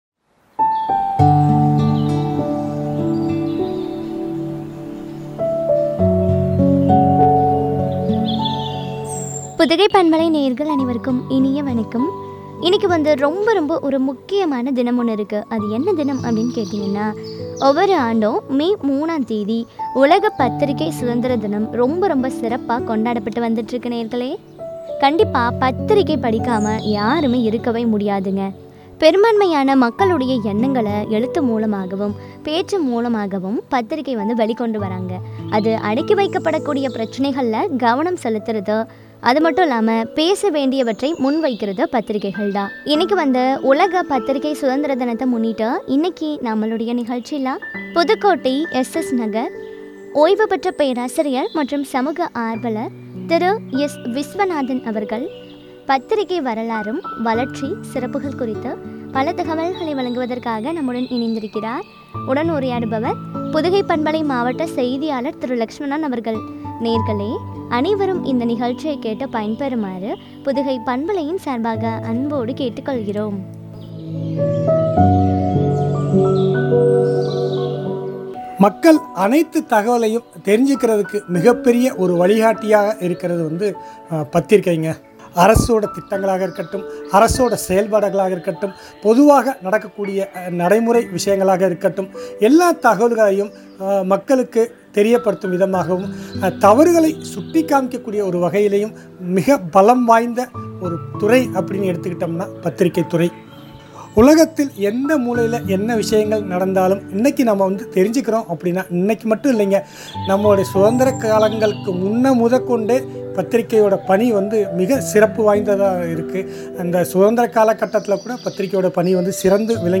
பத்திரிகை வரலாறு, வளர்ச்சி, சிறப்புகள் பற்றிய உரையாடல்.